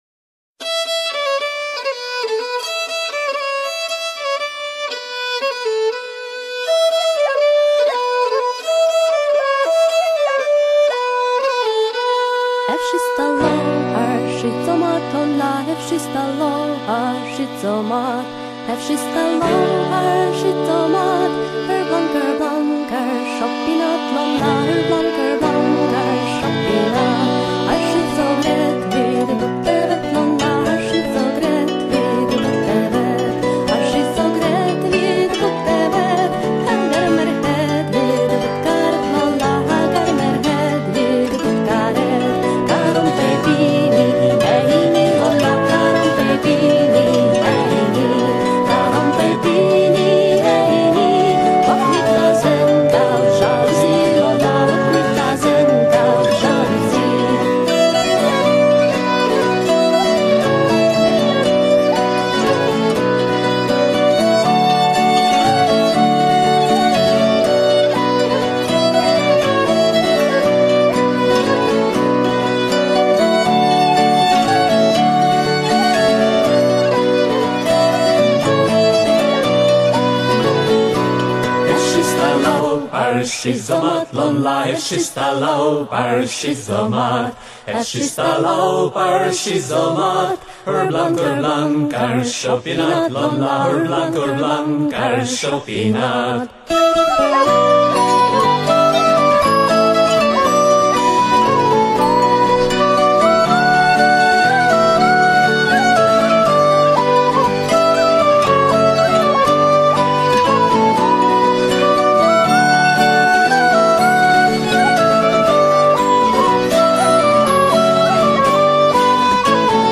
древняя бретонская песня